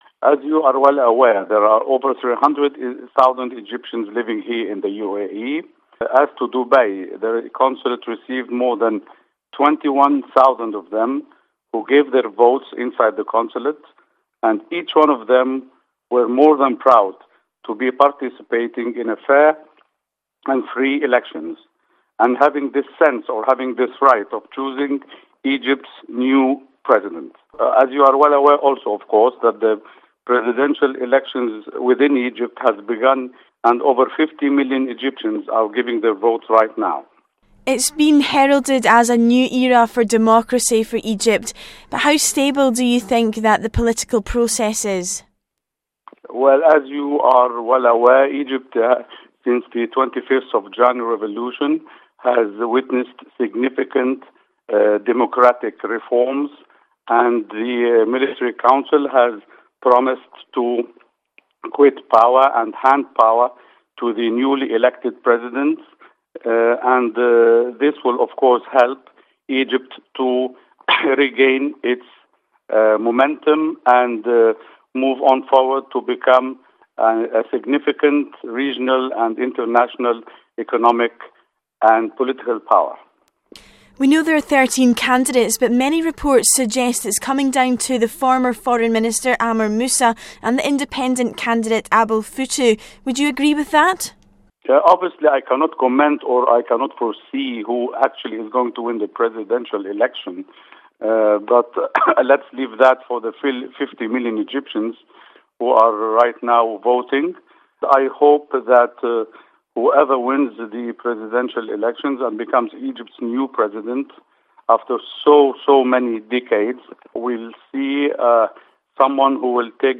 Egyptian Elections: Exclusive interview with Consulate General